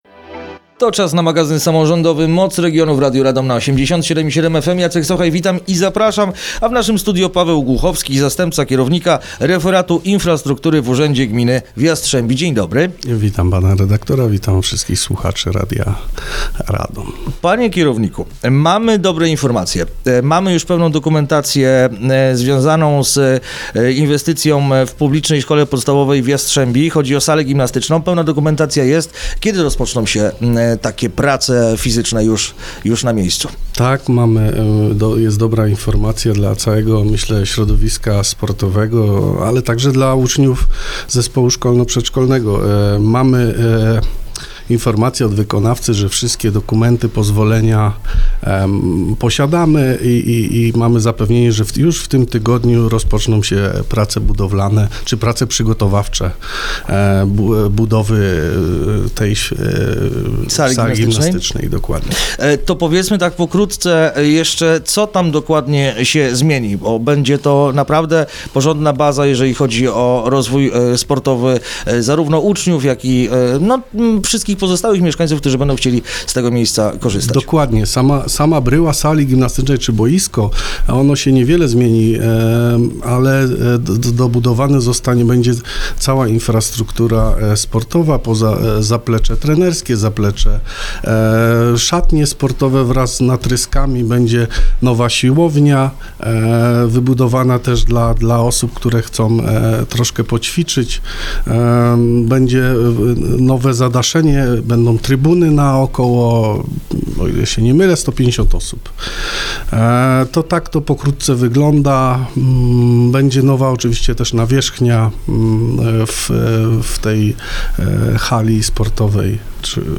Rozmowa dostępna jest również na facebookowym profilu Radia Radom: